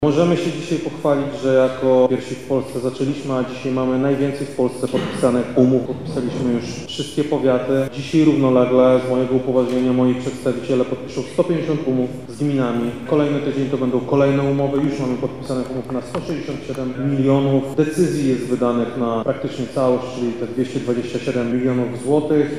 Dzisiaj podpisujemy umowę z samorządem województwa lubelskiego na ponad 10 milionów zł, w dużej mierze są to środki, które trafią do naszych lubelskich szpitali – mówi Krzysztof Komorski, wojewoda lubelski:
Krzysztof-Komorski-1.mp3